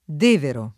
[ d % vero ]